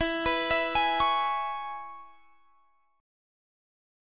Listen to E6 strummed